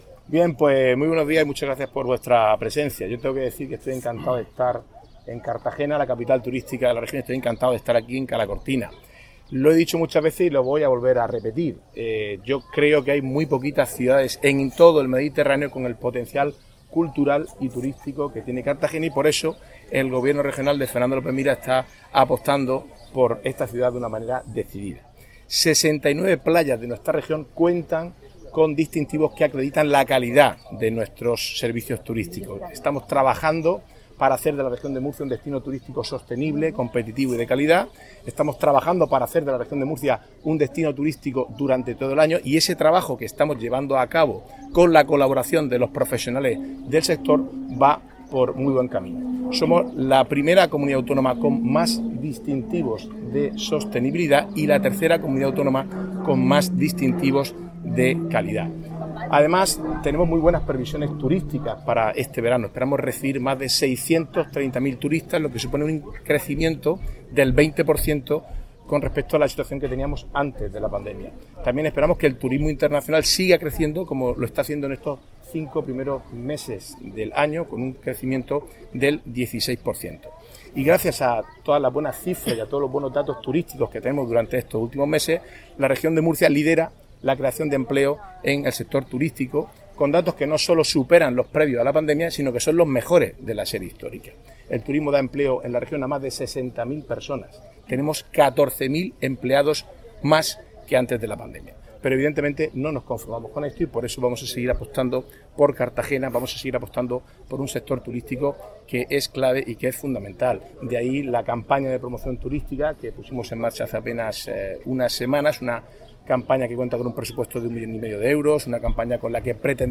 Así lo ha indicado este viernes, 14 de julio, la alcaldesa, Noelia Arroyo, durante el acto de izado de banderas celebrado en Cala Cortina.